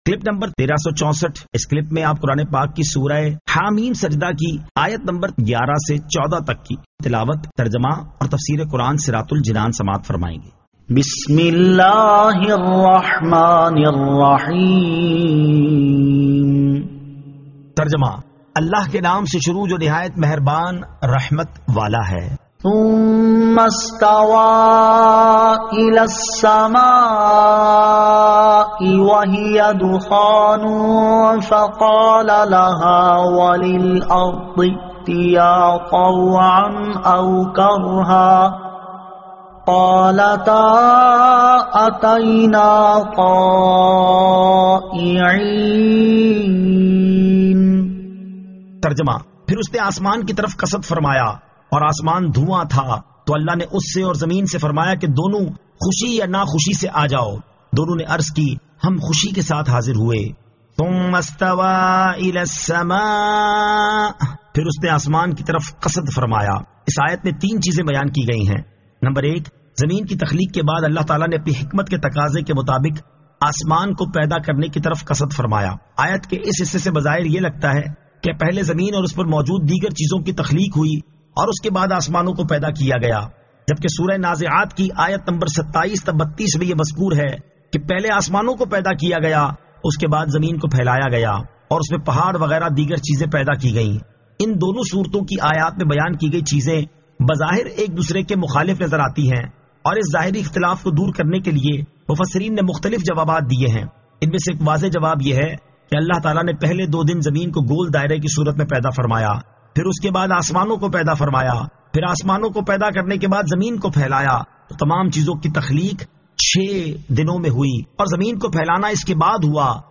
Surah Ha-Meem As-Sajdah 11 To 14 Tilawat , Tarjama , Tafseer